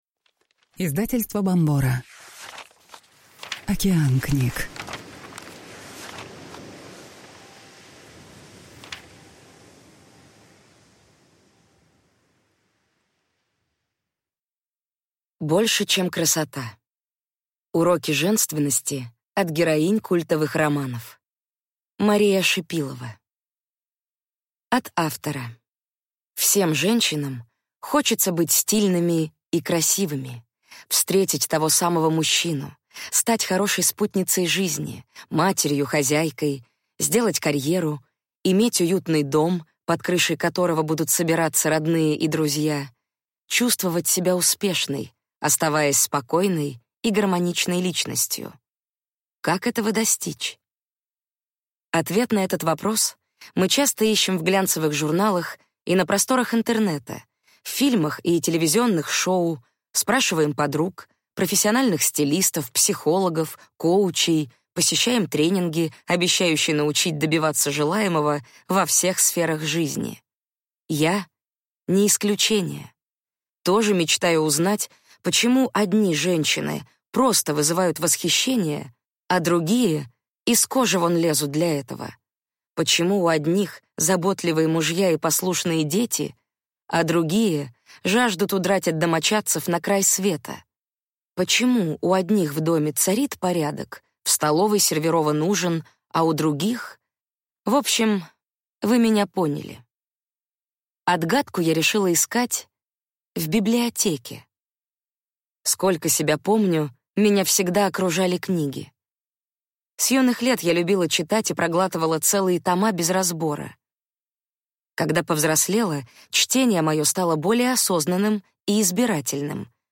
Аудиокнига Больше, чем красота. Уроки женственности от героинь культовых романов | Библиотека аудиокниг